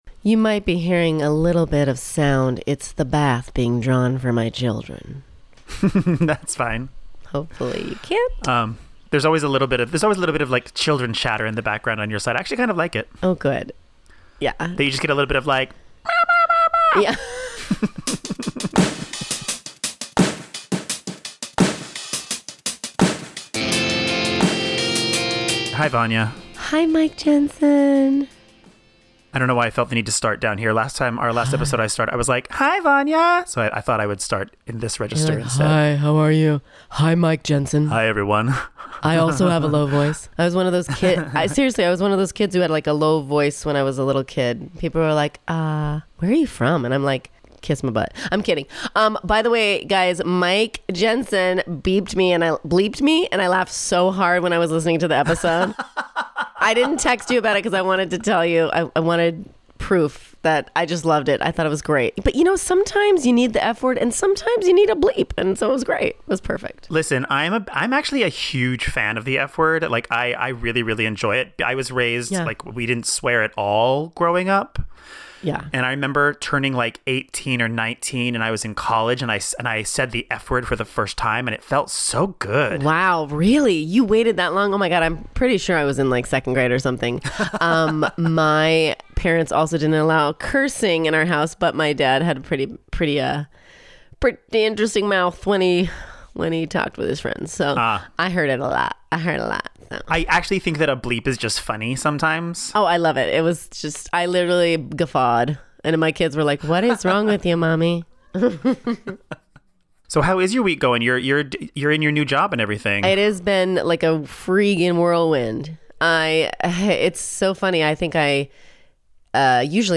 Plus, the sounds of happy children in the background.